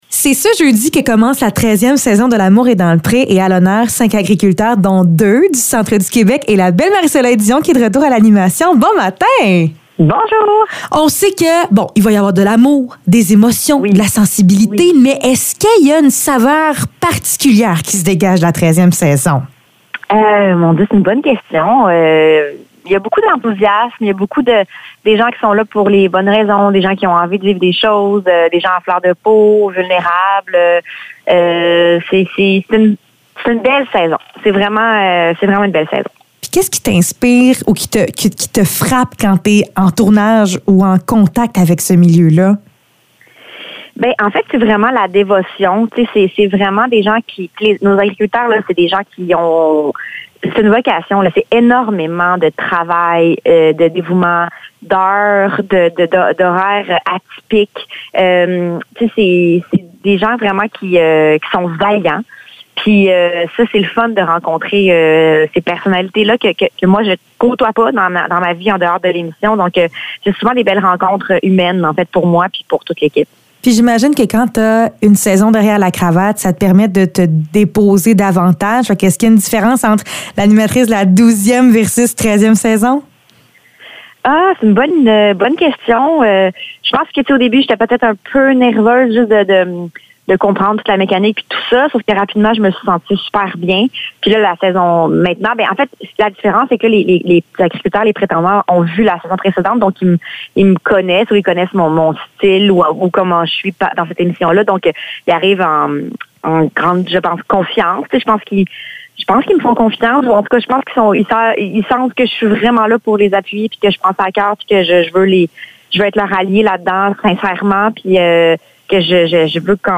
Entrevue avec Marie-Soleil Dion pour l’Amour est dans le pré